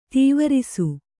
♪ tīvarisu